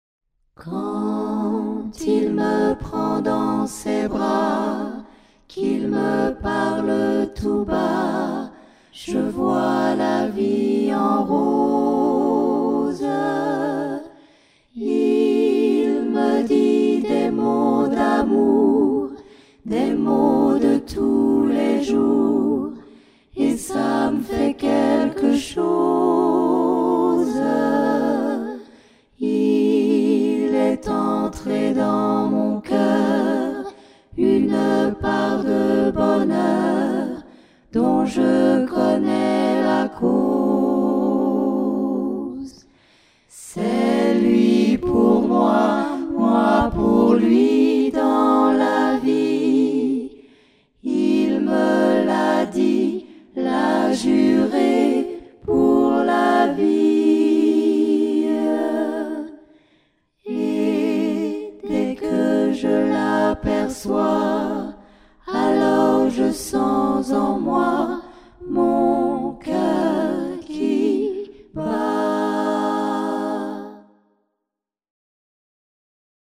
A Capella